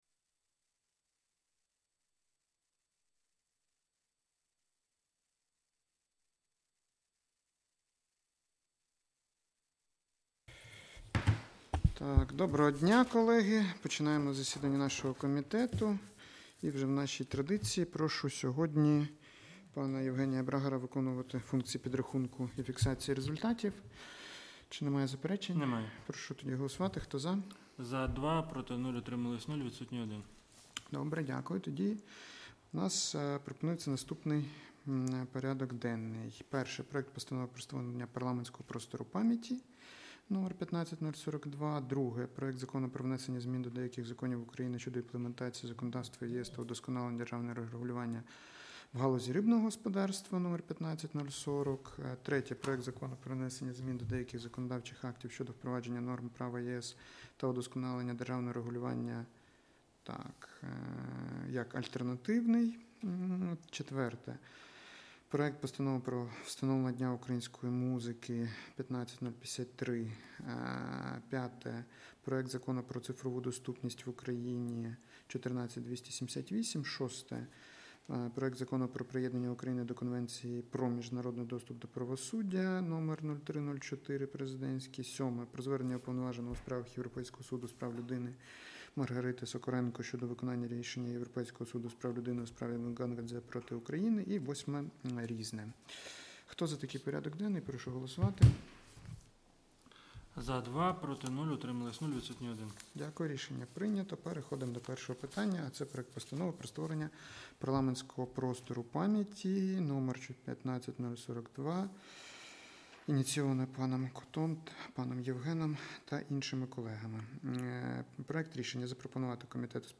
Аудіозапис засідання Комітету від 12 березня 2026р.